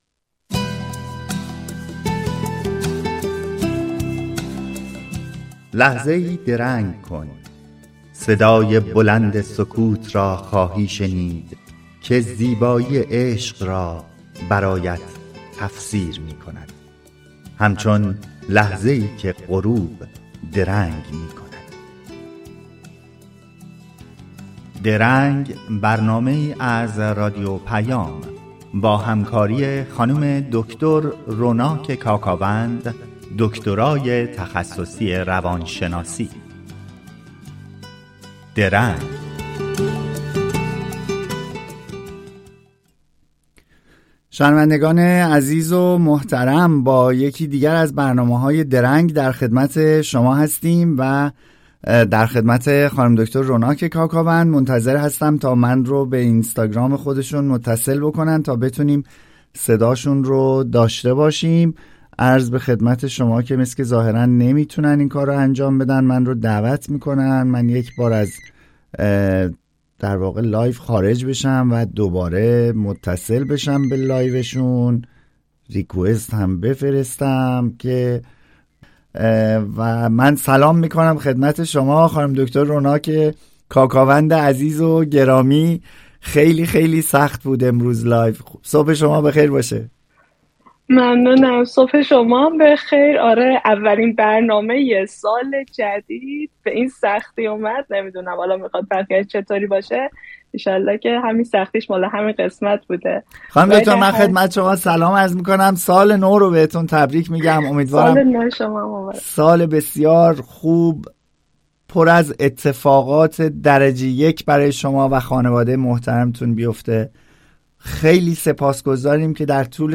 شما در این صفحه می‌توانید به برنامهٔ «درنگ» که هر هفته به‌صورت زنده از رادیو پیام گوتنبرگ سوئد پخش می‌شود، گوش دهید. این برنامه با هدف پرداختن به موضوعات متنوع اجتماعی، روانشناختی و فرهنگی تهیه و ارائه می‌شود.